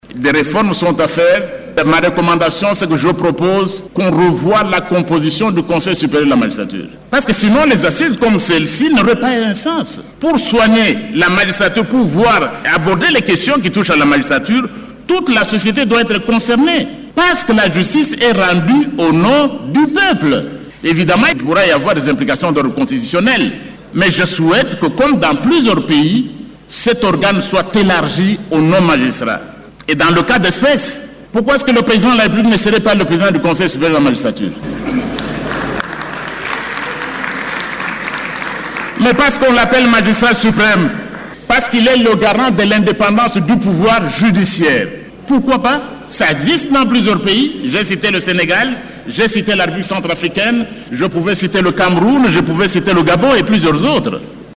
Devant les magistrats et auxiliaires de la justice ainsi que des justiciables réunis dans la salle des congrès du Centre financier de Kinshasa, André Mbata a proposé que le Chef de l’Etat devienne le président de cet organe, étant donné que la justice est une affaire de tous, elle ne doit être laissé aux seuls magistrats.
« Comme dans d’autres pays, je souhaite que cet organe soit élargi aux non-magistrats. Pourquoi le Président de la République ne serait-il pas le président du Conseil supérieur de la magistrature, étant donné qu’il est le garant de l’indépendance du pouvoir judiciaire ? », s’interroge André Mbata.